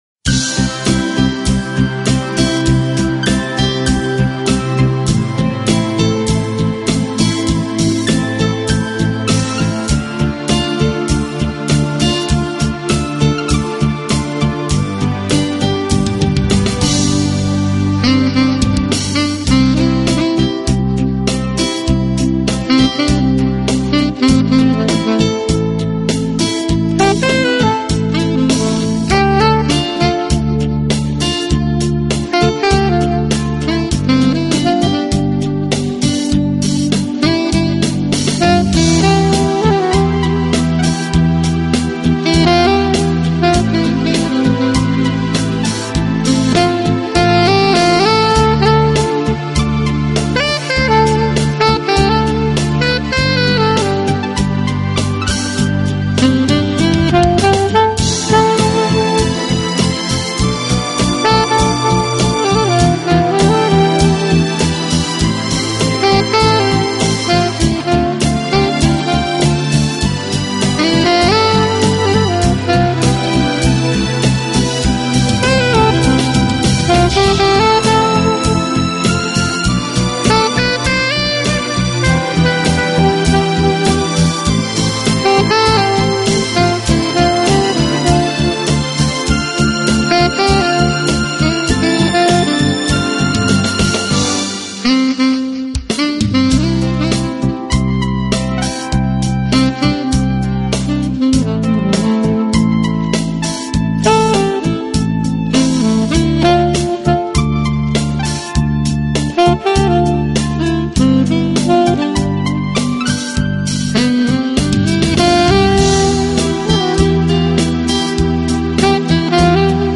Genero/Genre: Smoth Jazz/Orchestral Pop